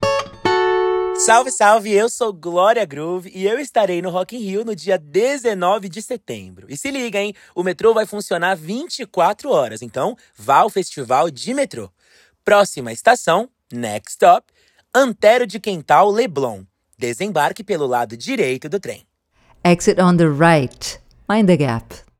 Os artistas gravaram mensagens anunciando o nome das estações e o funcionamento 24 horas do sistema metroviário durante os sete dias de evento.
ZS_MetroRio_Gloria-Groove_Antero-1.wav